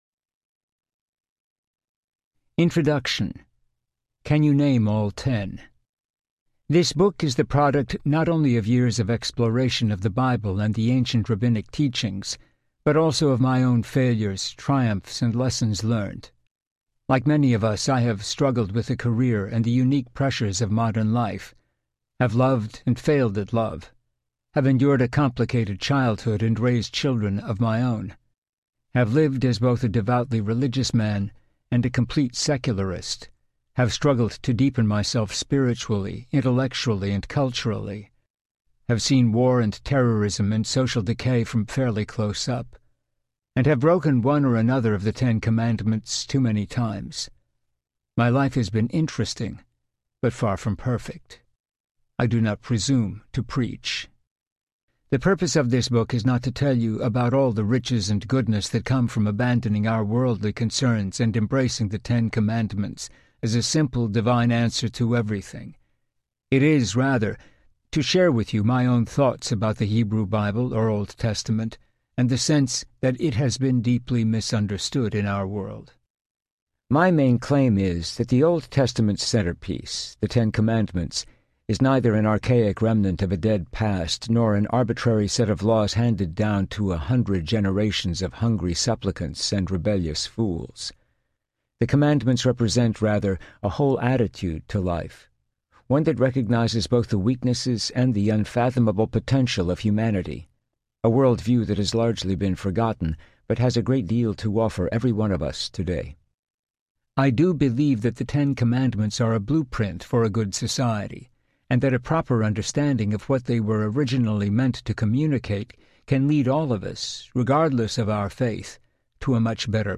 The Ten Commandments Audiobook
Narrator
8.5 Hrs. – Unabridged